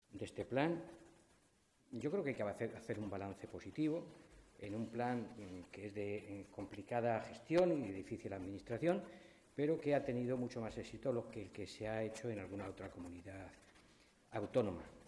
Mora, que ha realizado estas declaraciones momentos antes del inicio de la comisión de Economía y Empleo en las Cortes regionales, también quiso poner en valor que este plan ha conseguido obtener mejores resultados que los que han obtenido planes similares en otras comunidades autónomas.
Cortes de audio de la rueda de prensa